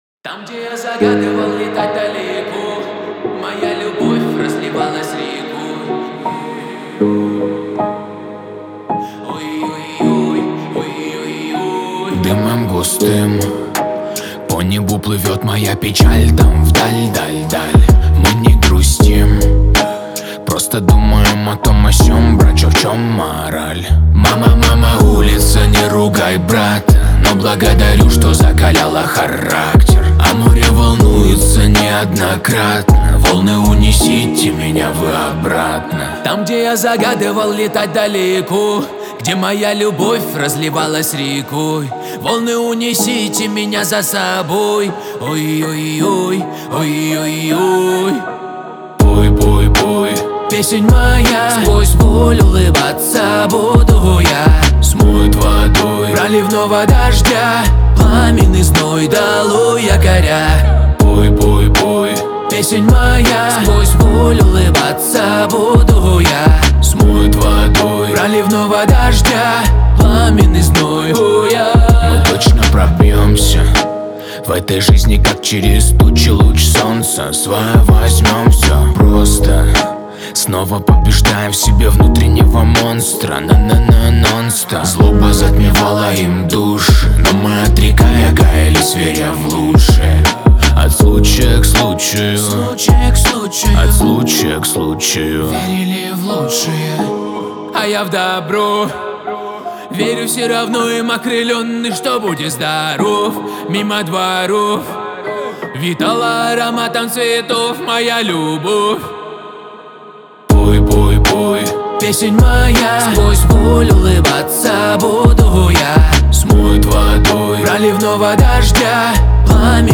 Категория: Поп